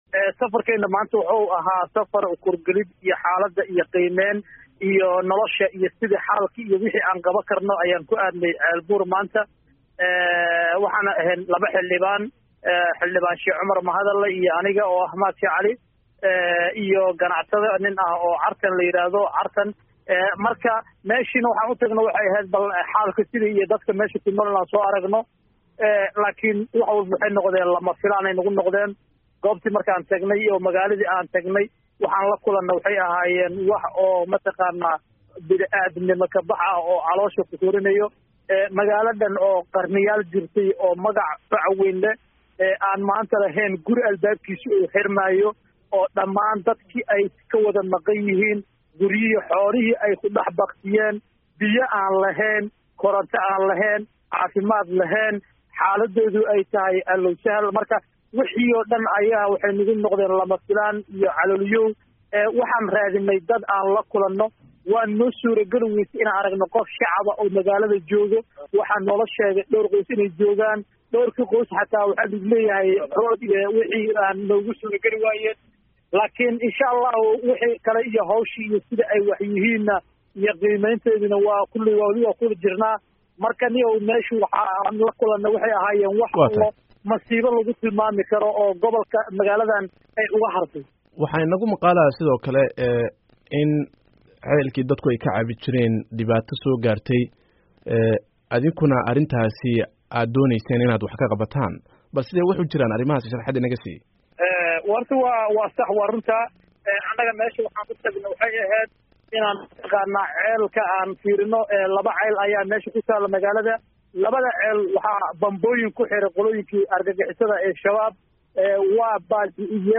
Wareysi: Xildhibaan Mahad